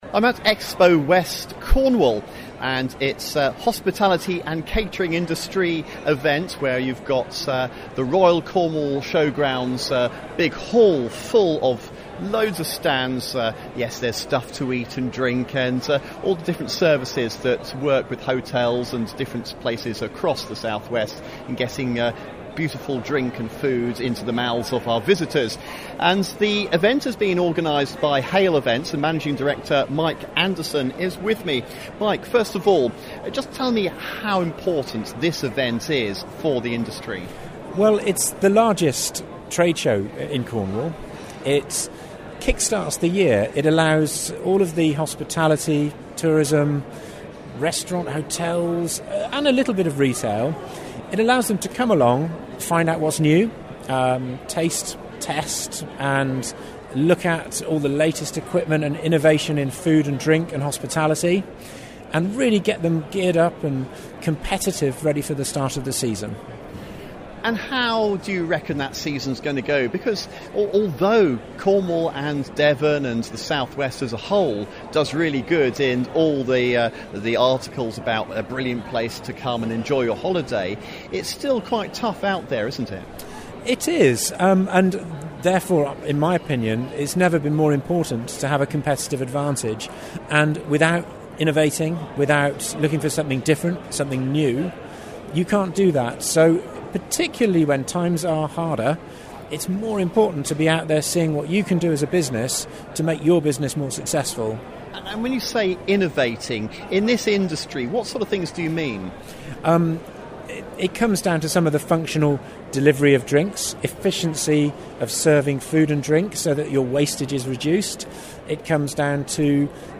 visits Expowest at the Royal Cornwall Showground